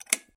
click.m4a